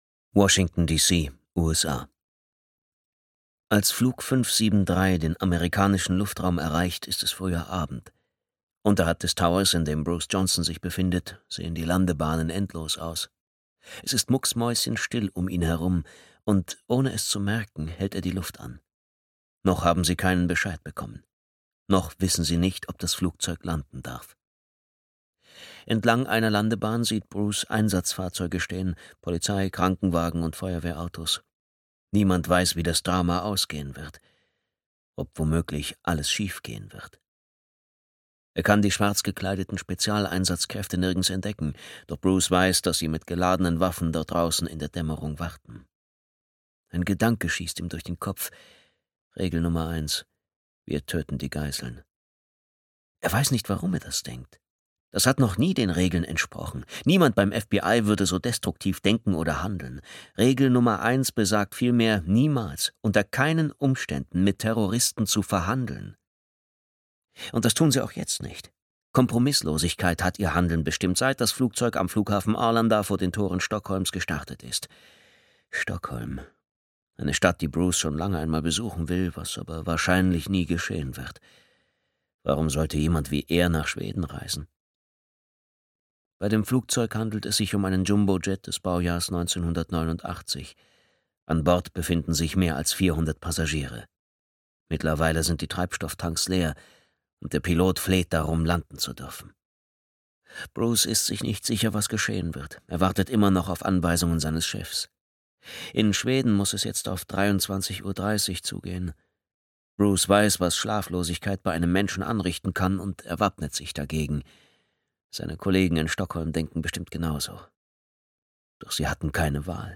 Himmelschlüssel (DE) audiokniha
Ukázka z knihy